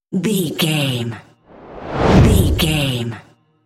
Whoosh deep fast
Sound Effects
Fast
dark
intense